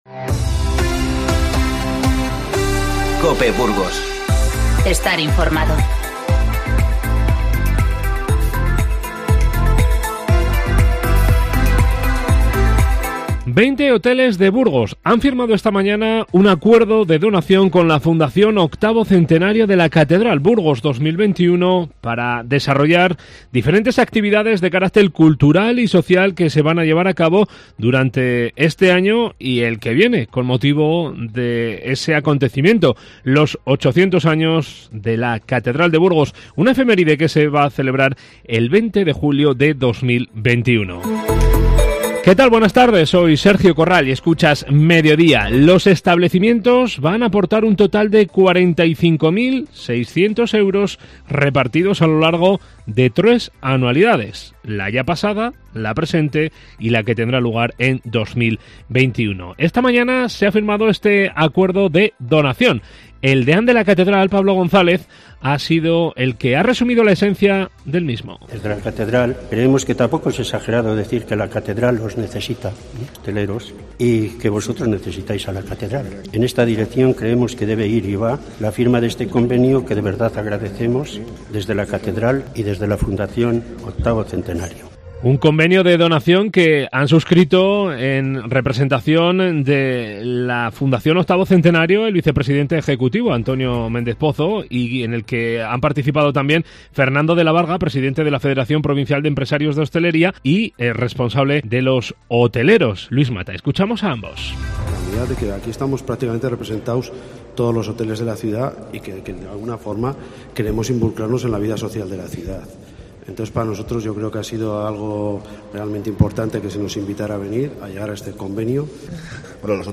Informativo 28-01-20